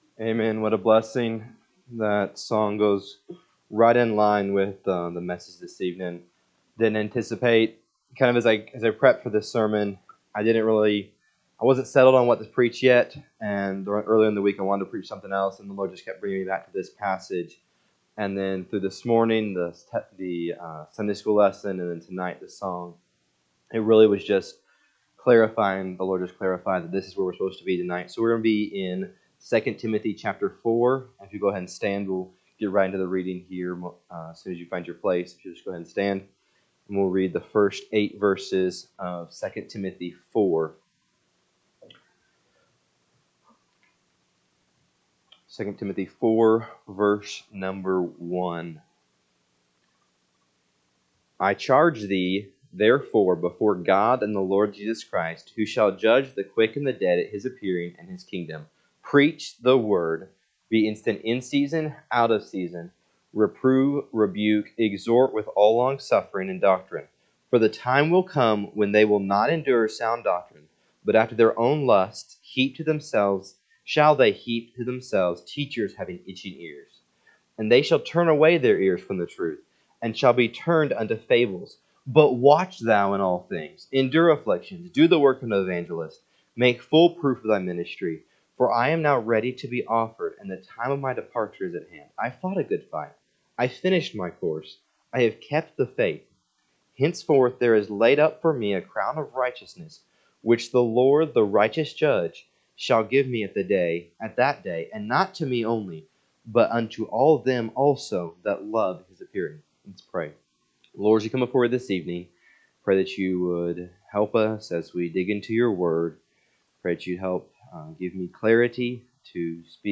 June 29, 2025 pm Service 2 Timothy 4:1-8 (KJB) 4 I charge thee therefore before God, and the Lord Jesus Christ, who shall judge the quick and the dead at his appearing and his kingdom; 2 …